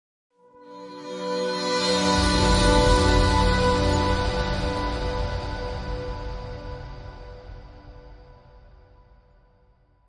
Intro para videos, estilo cinematográfico: Efectos de sonido
Su naturaleza cinematográfica ayudará a que tus introducciones sean memorables y dejen una impresión de calidad en tu audiencia.
Tipo: sound_effect
Intro cinematico.mp3